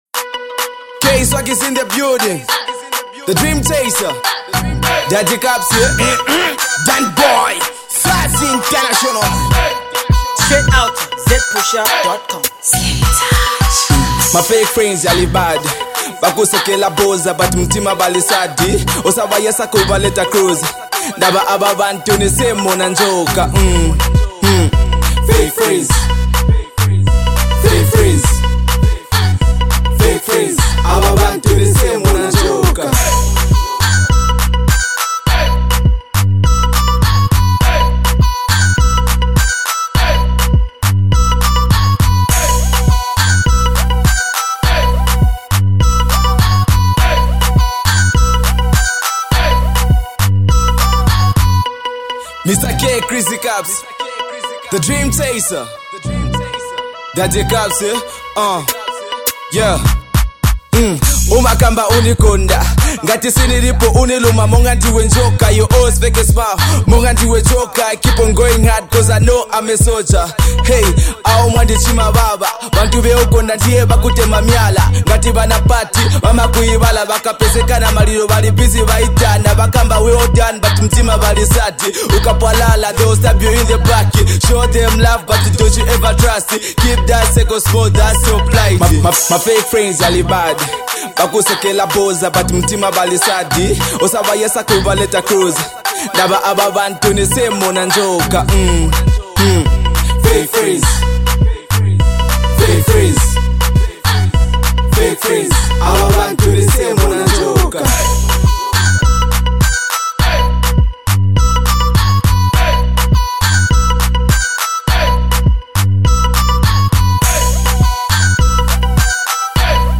A new Rap-Duo known as